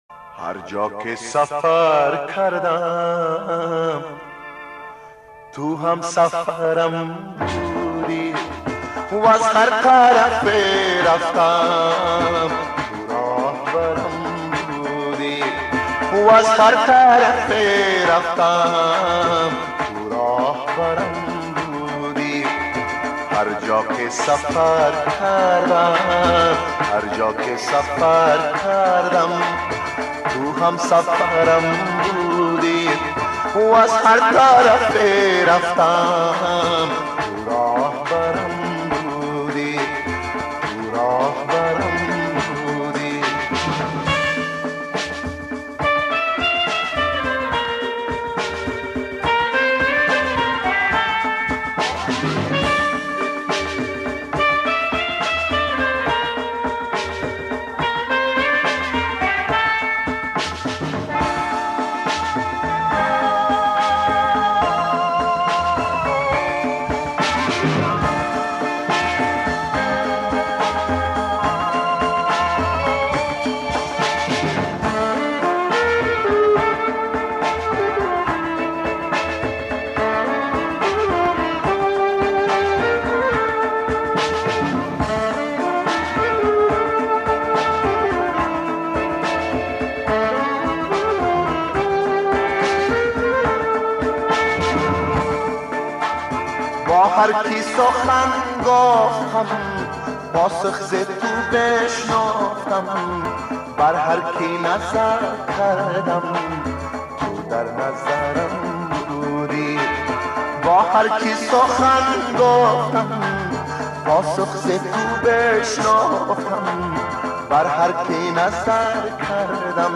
Afghan Songs